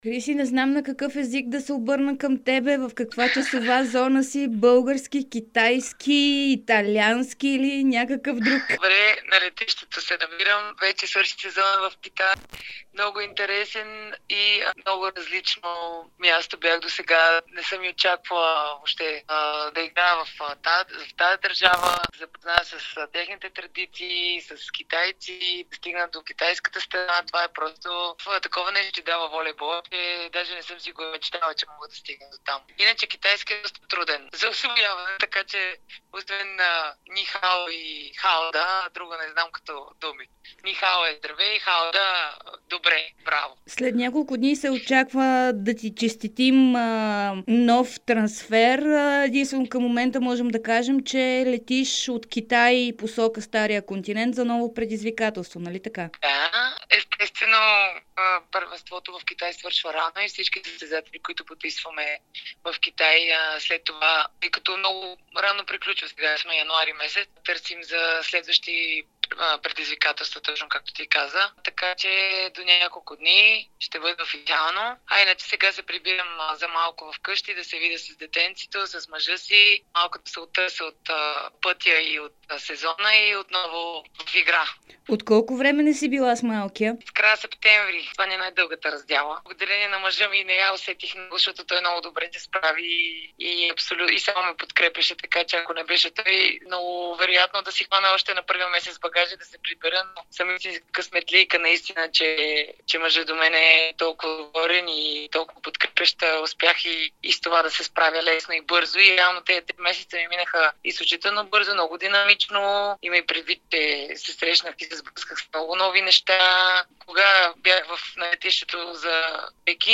Специално за Дарик радио и dsport българската волейболистка Христина Вучкова на път от Китай за България разказа за впечатленията си от местното първенство, за мерките срещу коронавируса в Китай, както и за това, дали има бъдеще в националния отбор на България.